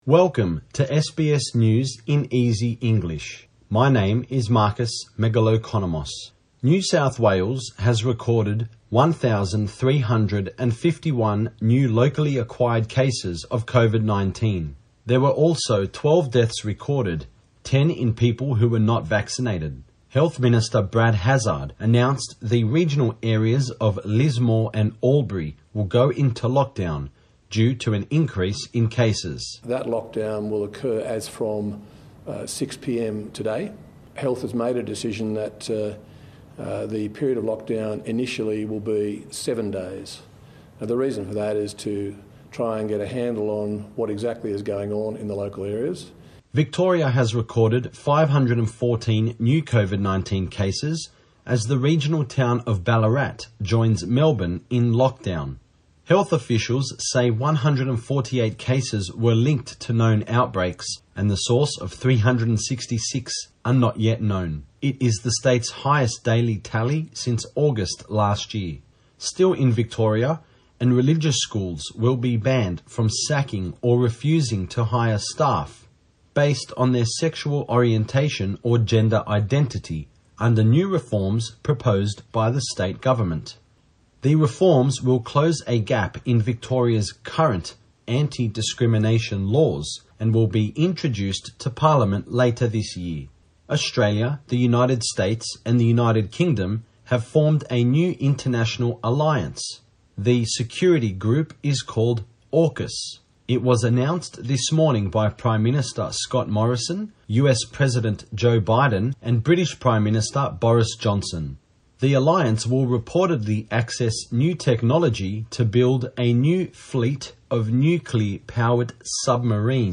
A daily 5 minute news wrap for English learners.